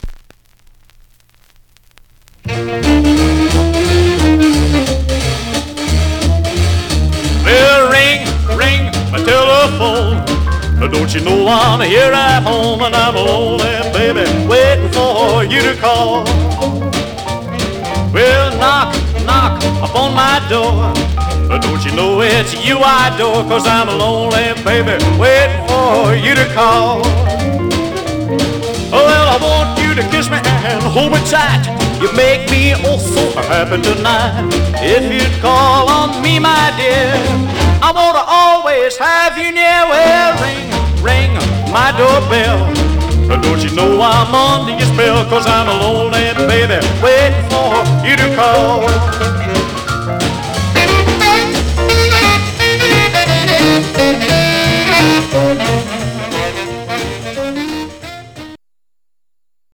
Surface noise/wear
Mono
Rockabilly